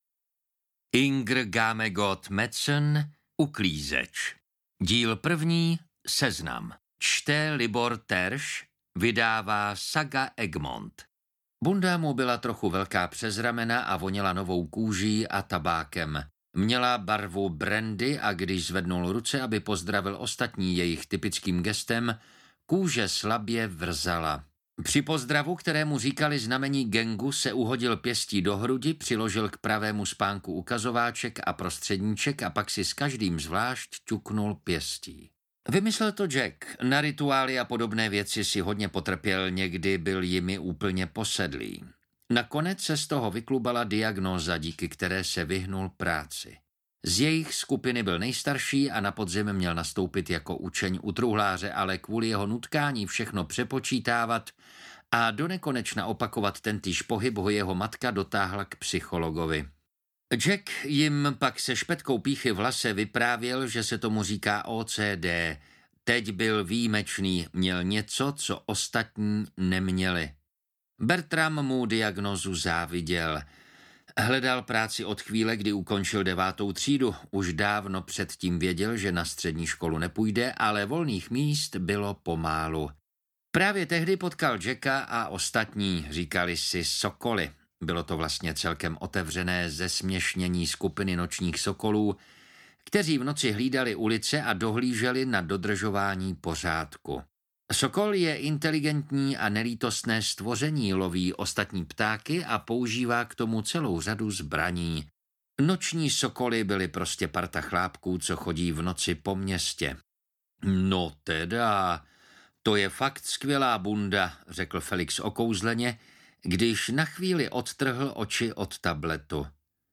Uklízeč audiokniha
Ukázka z knihy